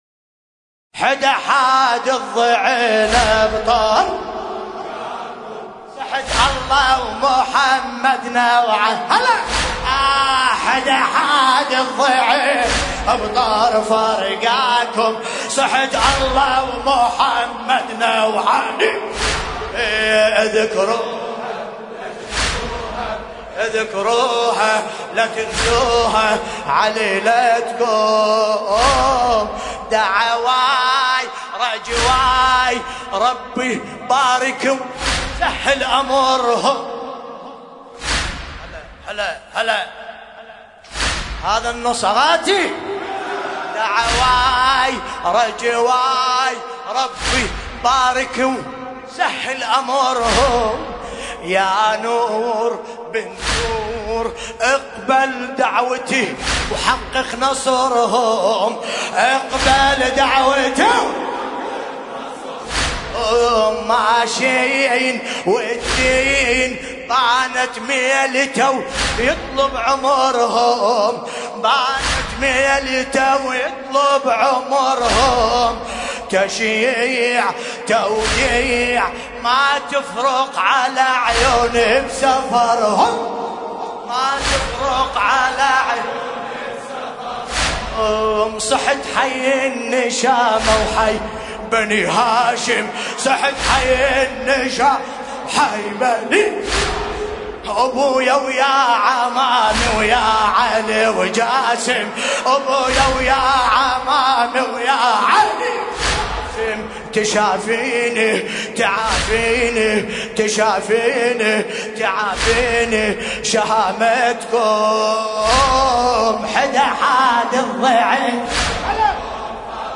المناسبة : ليلة ٣ محرم ١٤٤١ هـ لندن